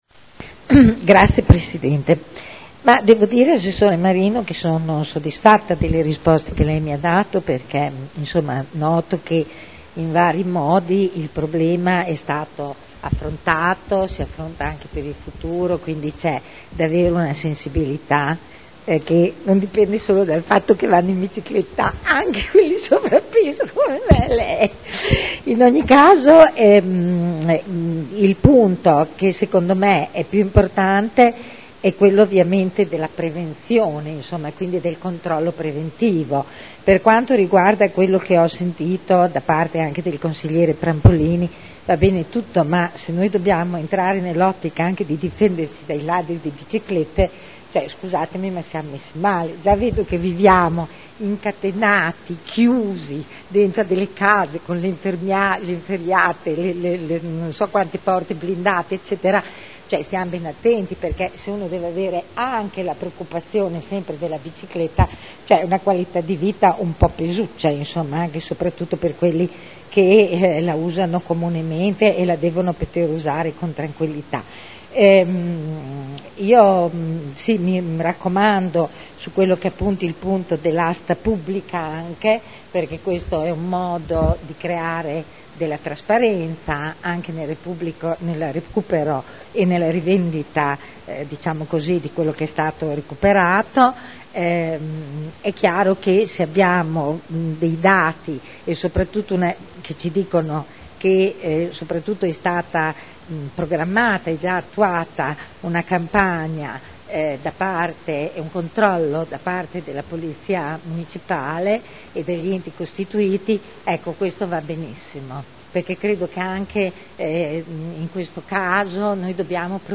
Seduta del 26 settembre 2011 Interrogazione della consigliera Rossi E. (IdV) avente per oggetto: “Furti di biciclette” Discussione